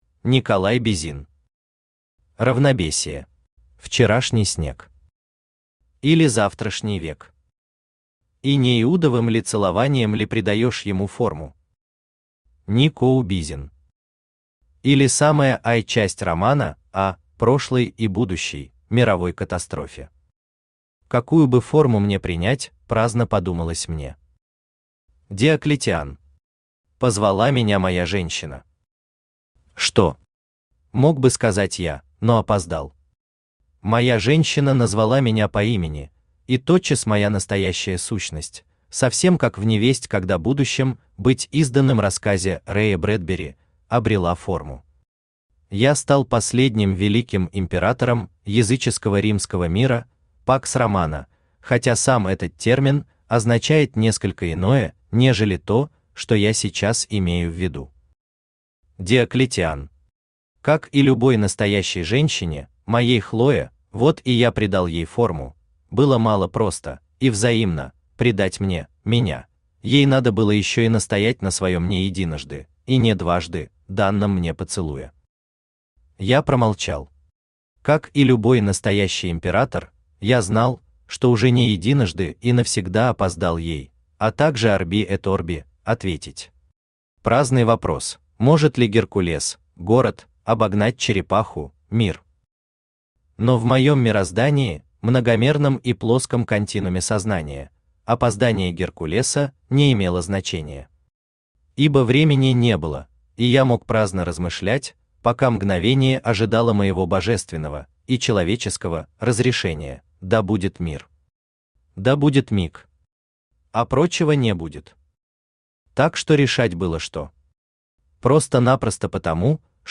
Aудиокнига Равнобесие Автор Николай Бизин Читает аудиокнигу Авточтец ЛитРес.